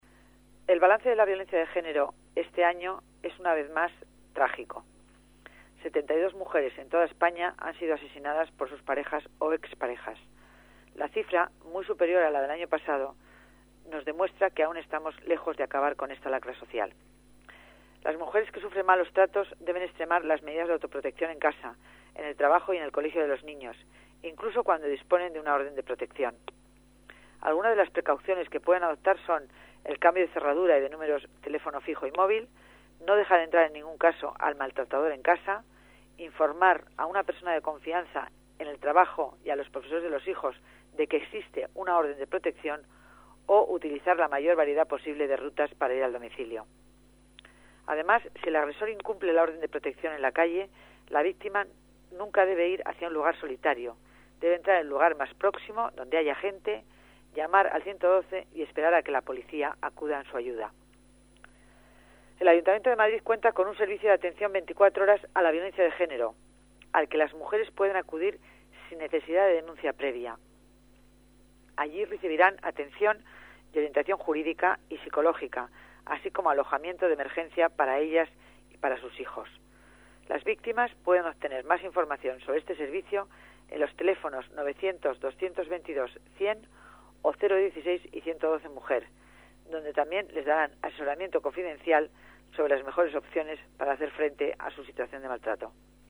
Nueva ventana:Declaraciones de Concepción Dancausa, delegada de Familia y Servicios Sociales